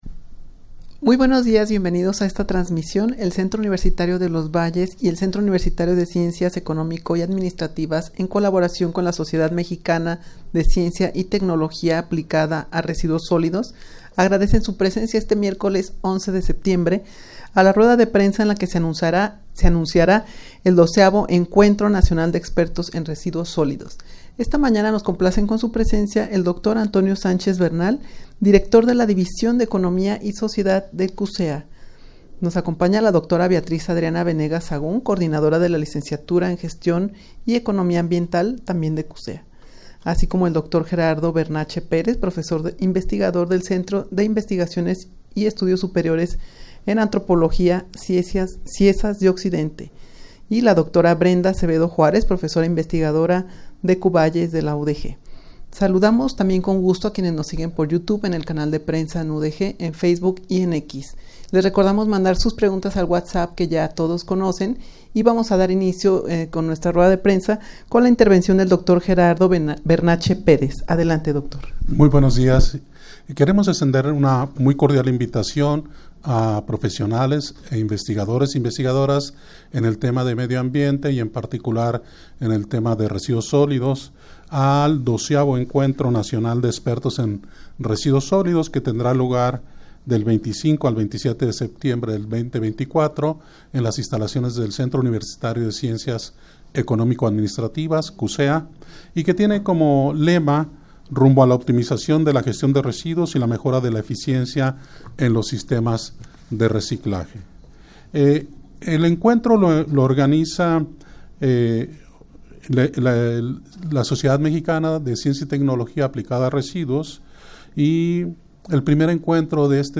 Audio de la Rueda de Prensa
rueda-de-prensa-en-el-que-se-anunciara-el-12-deg-encuentro-nacional-de-expertos-en-residuos-solidos.mp3